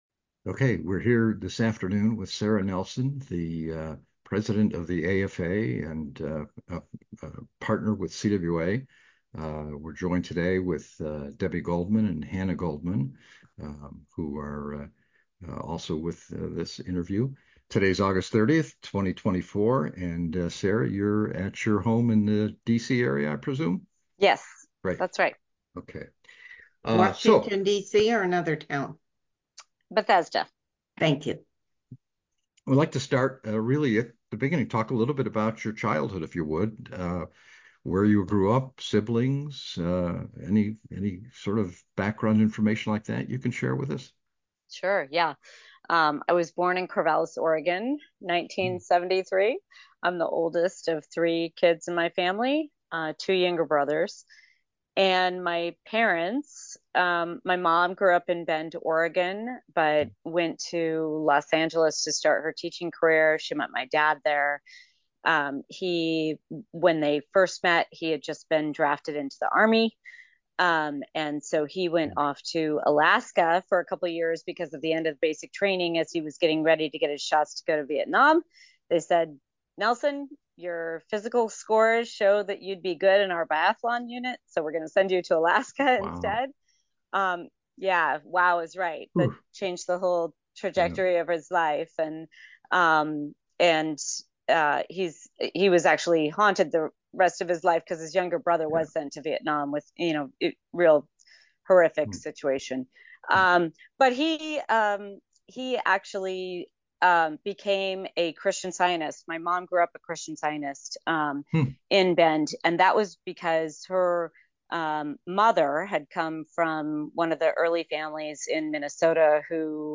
Audio file Download MP3 (53.78 MB) Download PDF (495.29 KB) Sara Nelson was elected president of the Association of Flight Attendants/CWA in 2014, the position she held at the time of this interview.
sara_nelson_oral_history.mp3